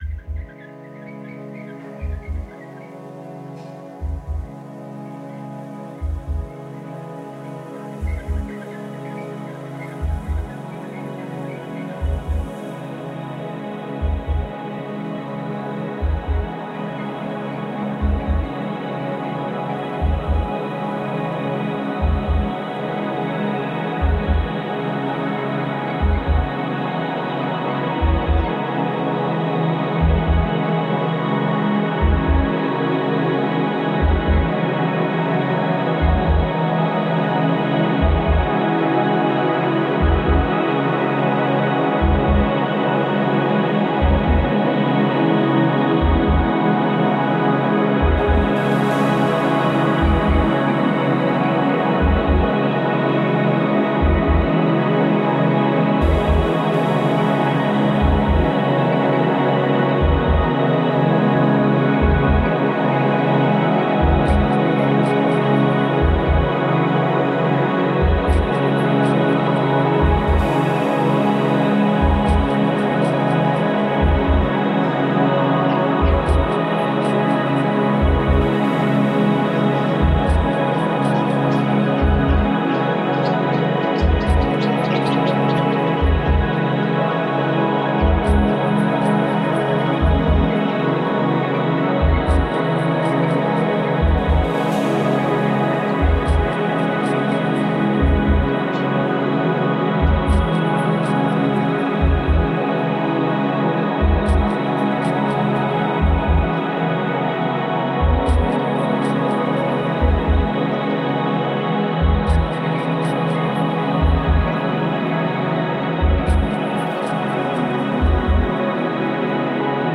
Birds nestled on mango trees in Phnom Penh, Cambodia.
ambient composition